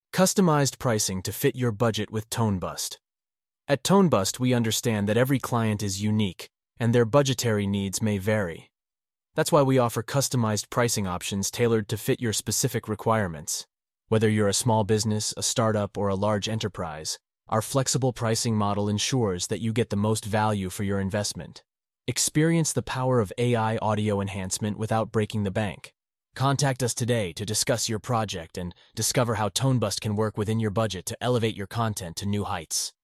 Artificial Intelligence Voiceovers